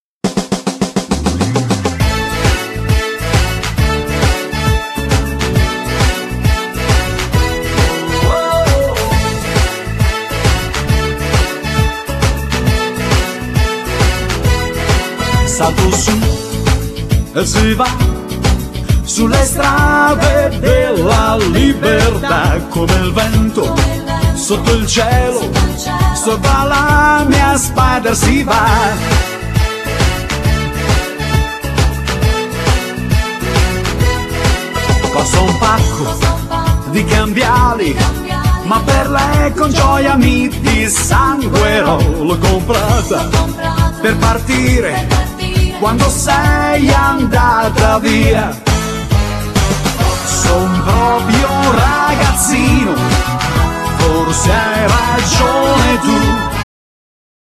Genere : Liscio - folk